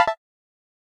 Techmino/media/effect/chiptune/uncheck.ogg at 97f4795d4e9d718a905dbee86770ef30bf053dfb
uncheck.ogg